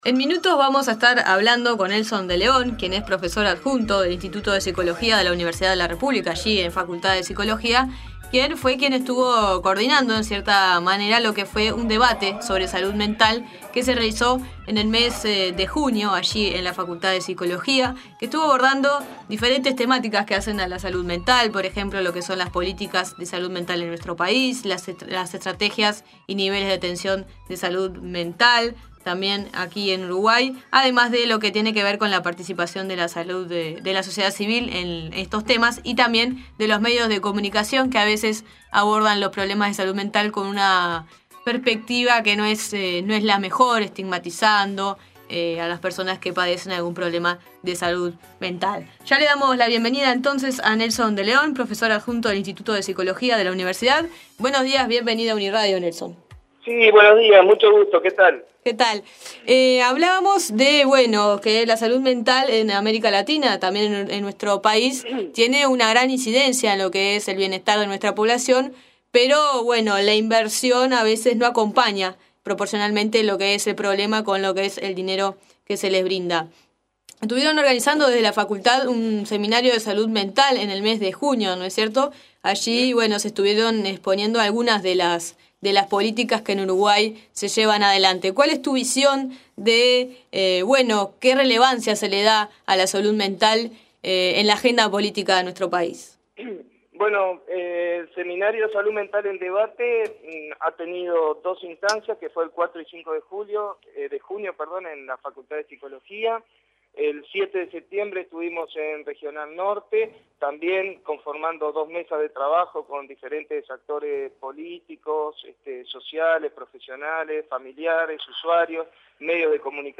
En La Nueva Mañana, entrevistamos